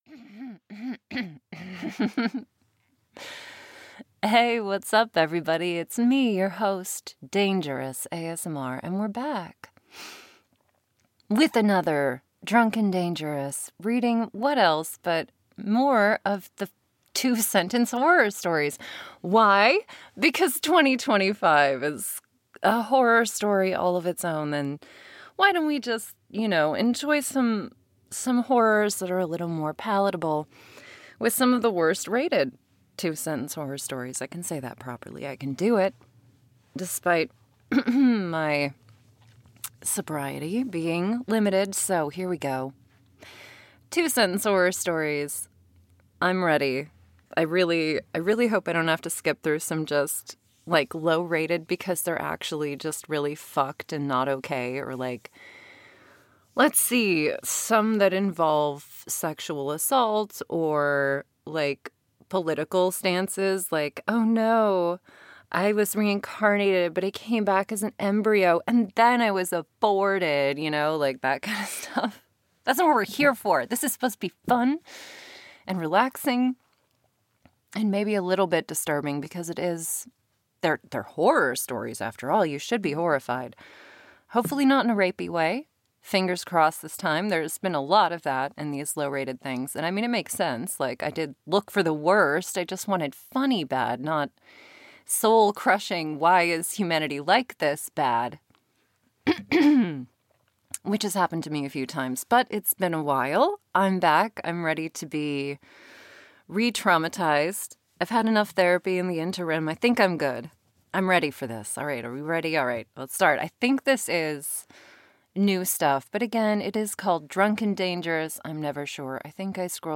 Made better by your mocking/questioning narration and the comments section.